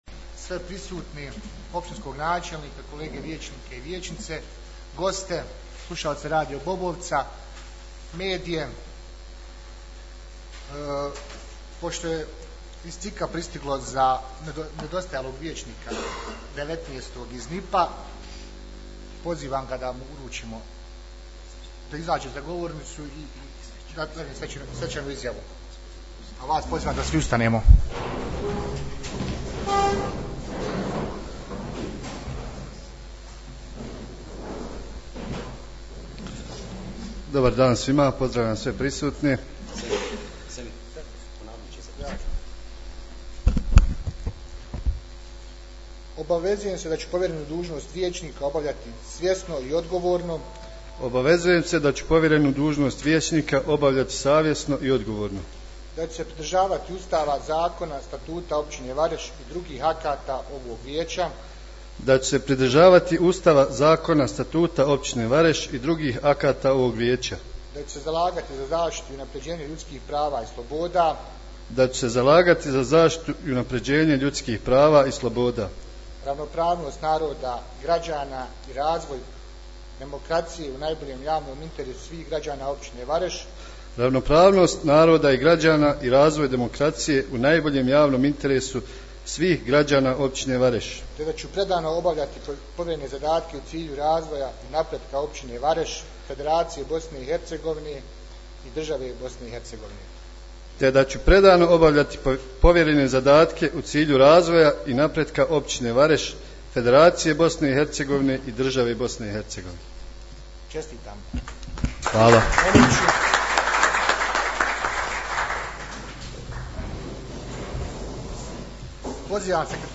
2.sjednica Općinskog vijeća Vareš u novom sazivu